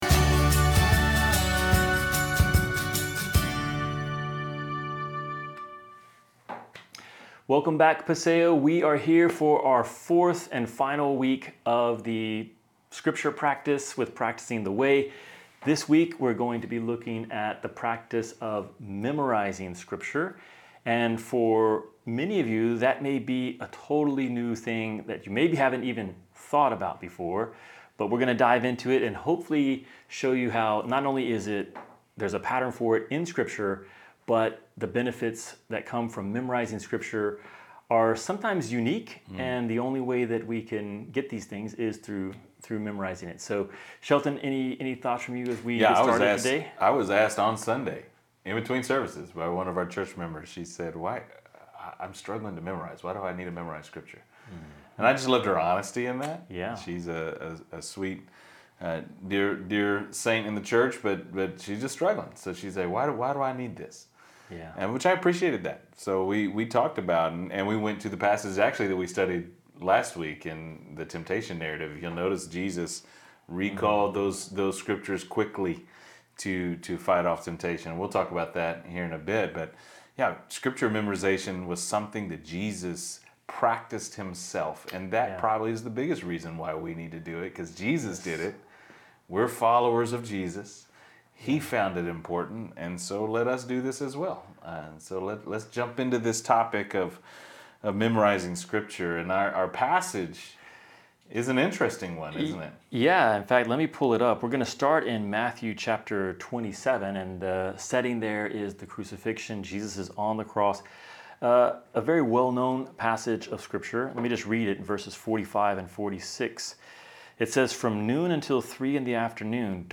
Listen to learn more about: The example of Jesus on the cross Why memorization matters How the Holy Spirit helps us Tips to practice memorizing the Bible this week We hope this conversation encourages you in your faith!